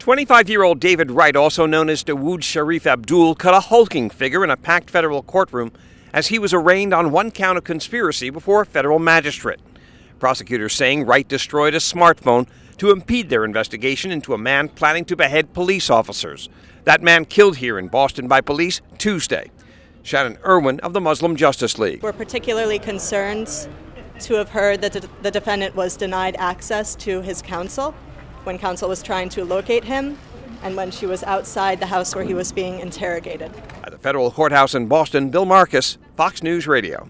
FROM THE FEDERAL COURTHOUSE IN BOSTON.